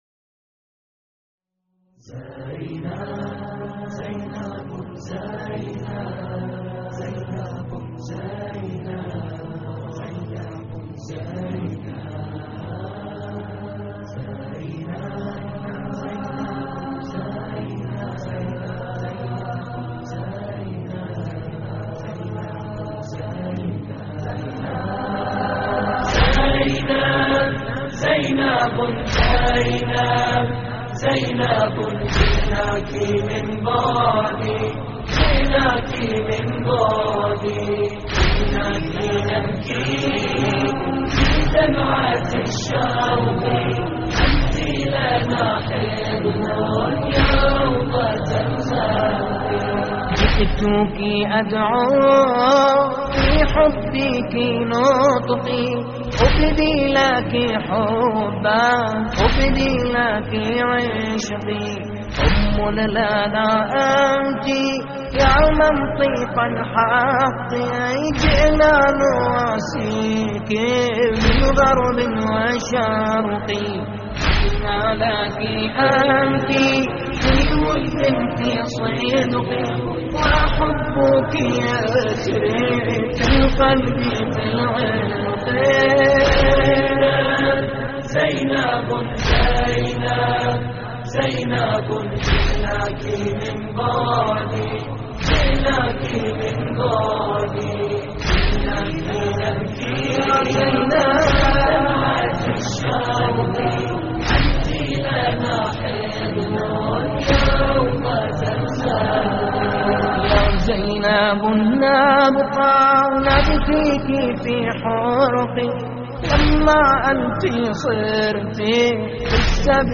اللطميات الحسينية
موقع يا حسين : اللطميات الحسينية زينبُ زينب جئناك من بعد جئناك نبكي - استديو «الناعي» لحفظ الملف في مجلد خاص اضغط بالزر الأيمن هنا ثم اختر (حفظ الهدف باسم - Save Target As) واختر المكان المناسب